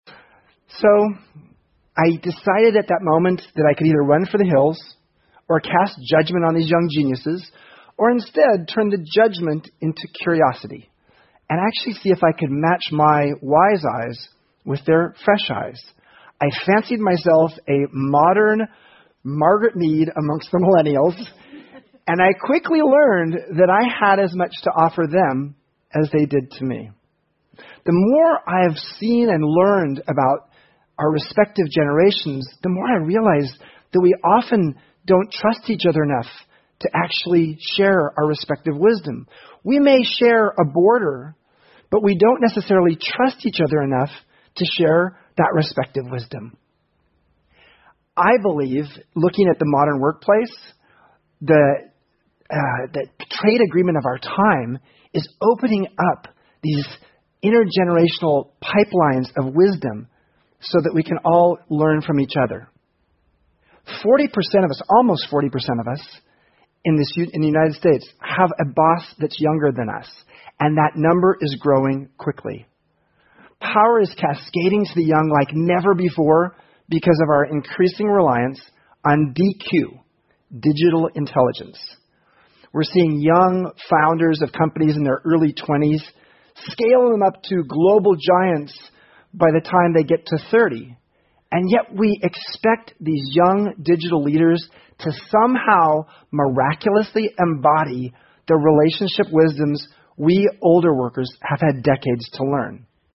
TED演讲:婴儿潮时期的人和千禧一代如何在工作中相互学习() 听力文件下载—在线英语听力室